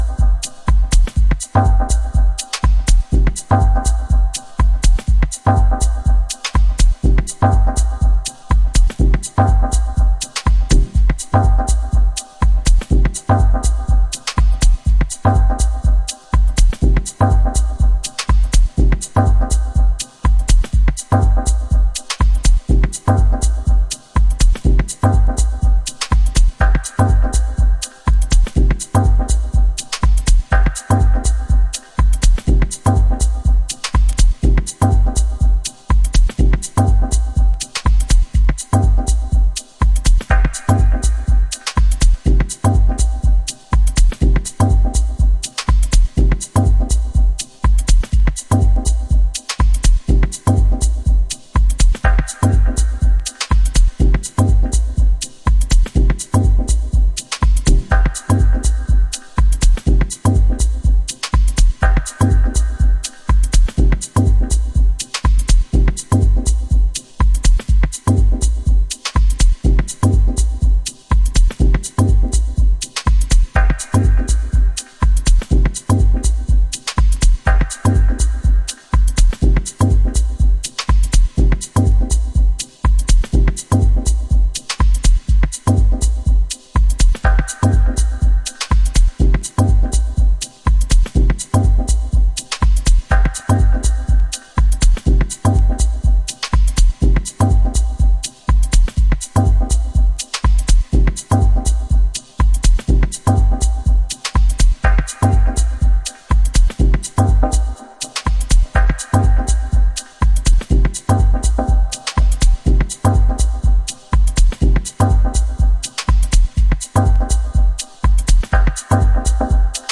House / Techno
音数を絞った
共に冷たい音像。地味渋最前線のダブテック/ディープテクノ。